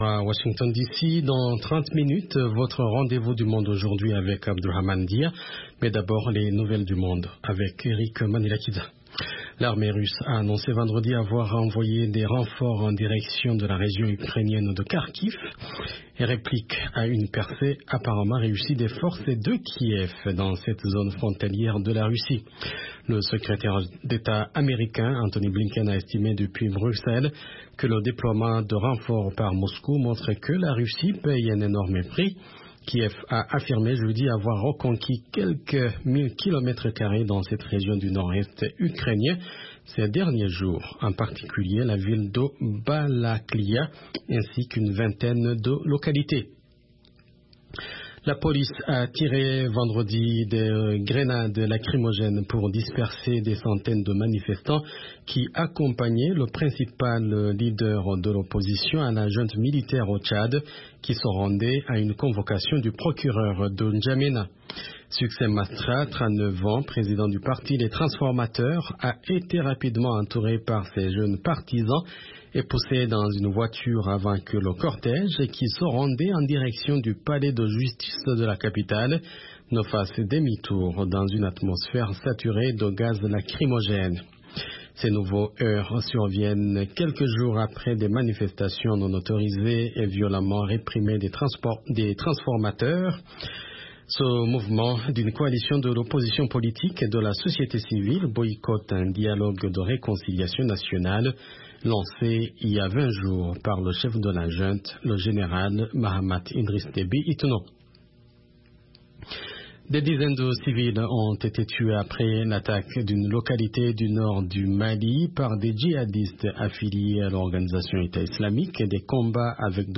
10 Minute Newscast